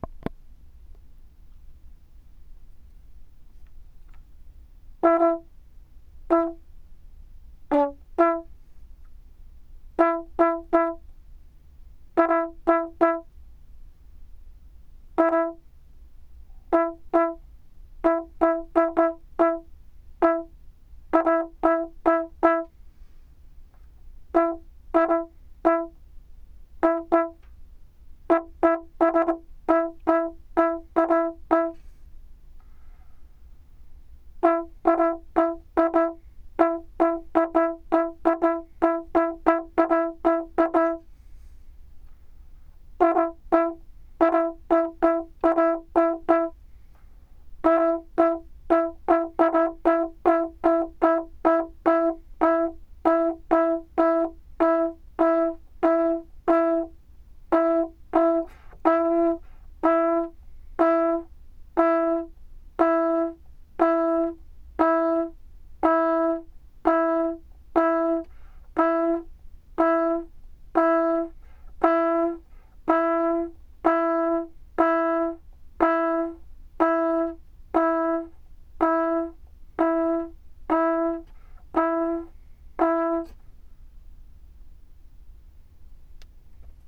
We have developed a tonal framework for musical improvisation based on new research in cell communication.
This three-part system suggests rich tonal blends. Two musicians play each part of the receptor. They play tones five notes apart. The ligands play individual tones in an erratic rhythm that isn’t in time with the receptors. Successful binding is consonant and coordinates the ligand’s rhythm with the receptors.